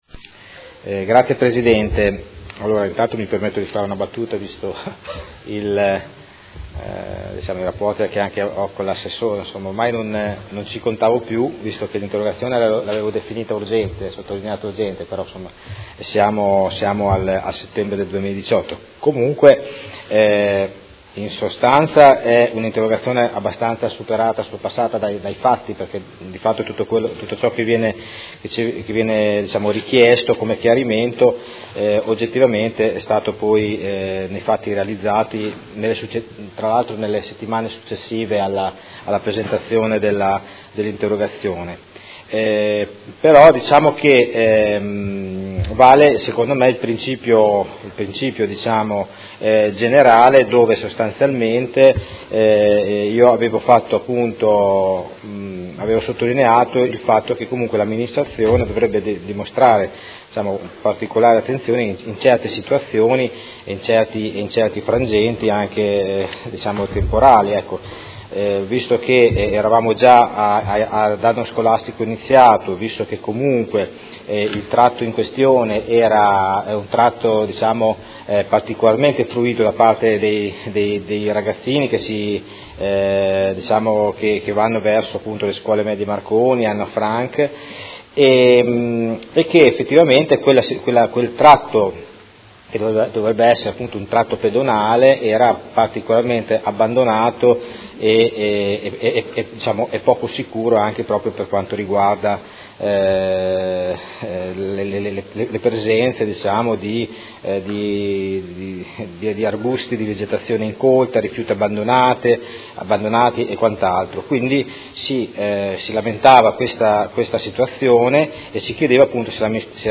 Seduta del 14/03/2019. Interrogazione del Consigliere Stella (Art1-MDP/Per Me Modena) avente per oggetto: Situazione di grave degrado ambientale in cui versa il percorso pedonale di Viale del Mercato